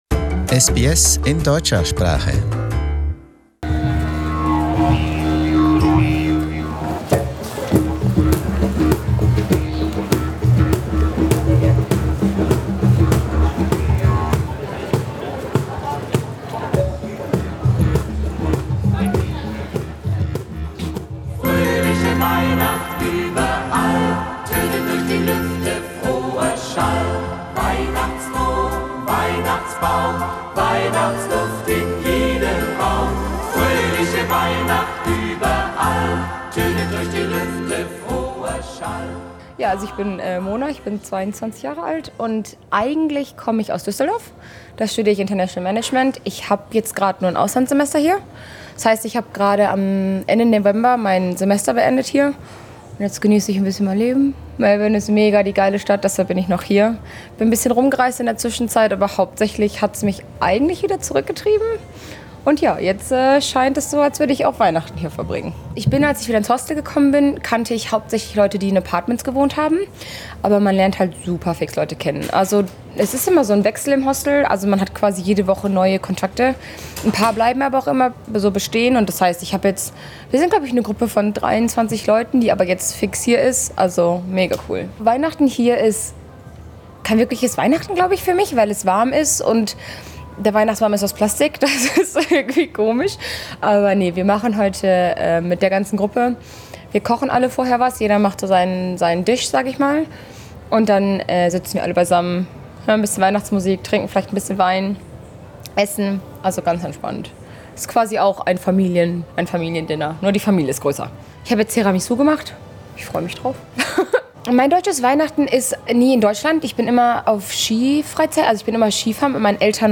We met up with a few of them at Melbourne's Federation Square and asked them what they really think about Christmas in Australia.